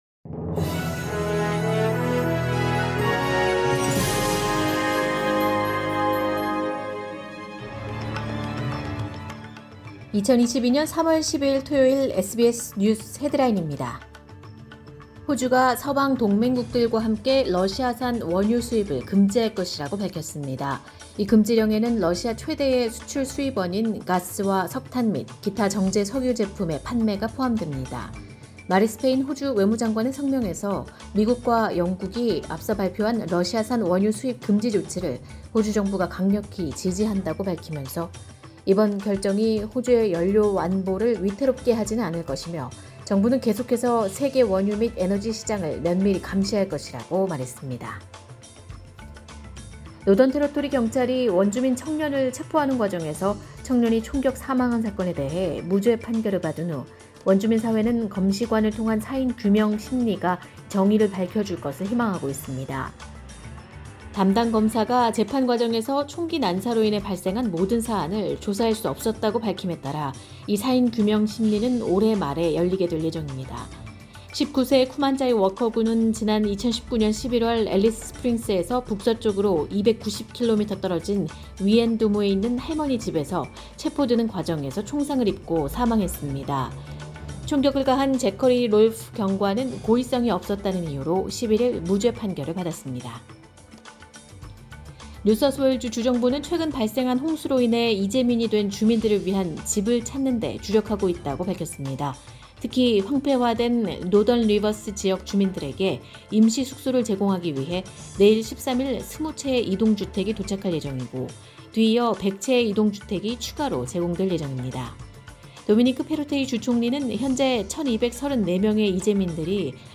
2022년 3월 12일 토요일 SBS 뉴스 헤드라인입니다.